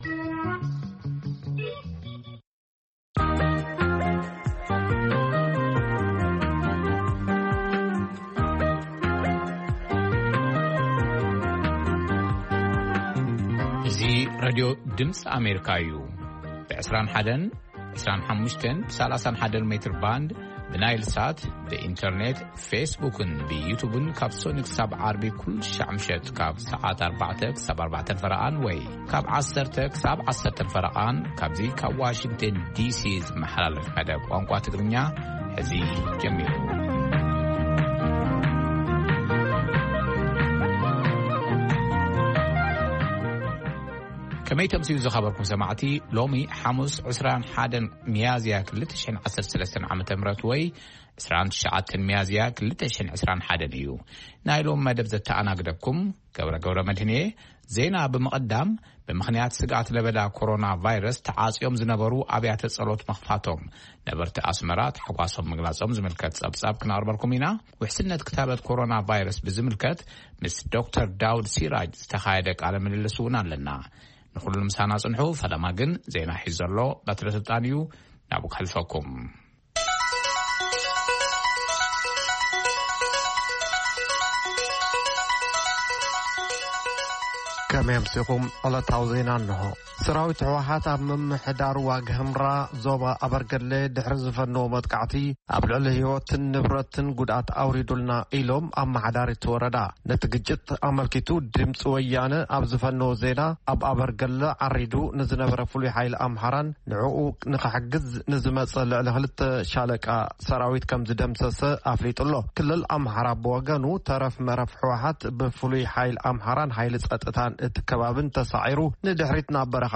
ፈነወ ትግርኛ ብናይ`ዚ መዓልቲ ዓበይቲ ዜና ይጅምር ። ካብ ኤርትራን ኢትዮጵያን ዝረኽቦም ቃለ-መጠይቓትን ሰሙናዊ መደባትን ድማ የስዕብ ። ሰሙናዊ መደባት ሓሙስ፡ መንእሰያት/ ጥዕና